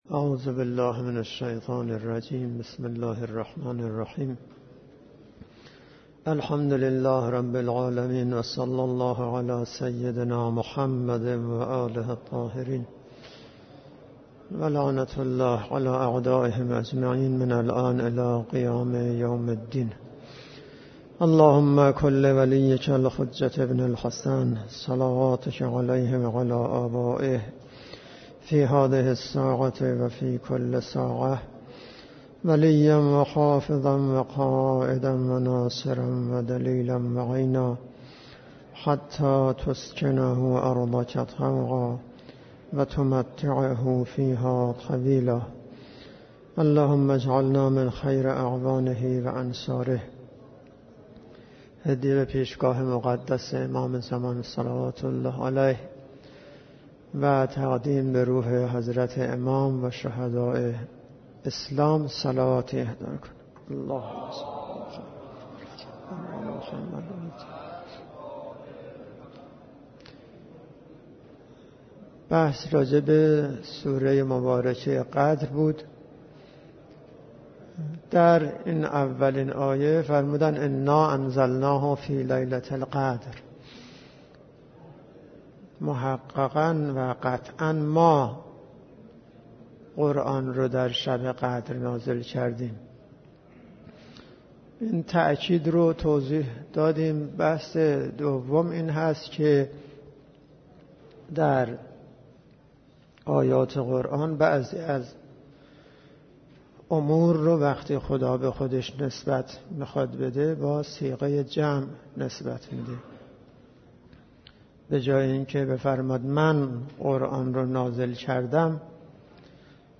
مجموعه صوتي‌ سخنرانيهاي ماه مبارک رمضان